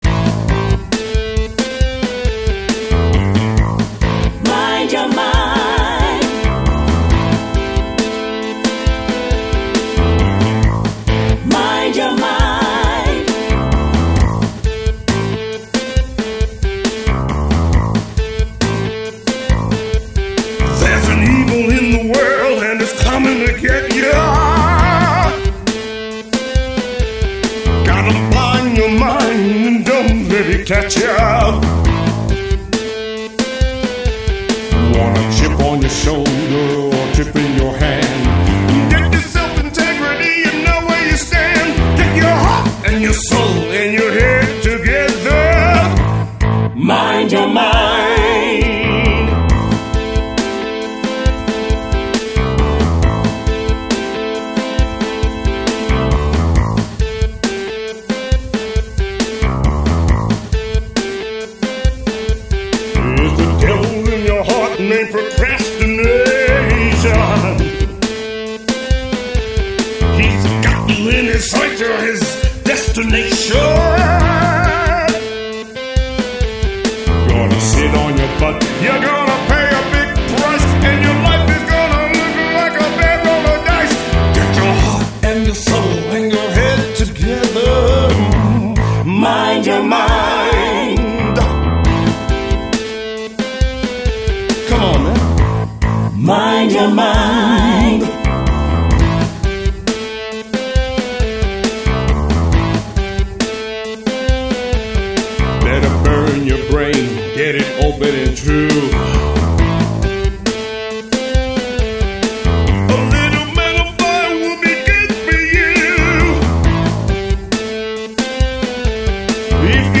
approx song time 3:10   Vocal M. Funk Rock with soul.